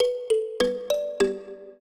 mbira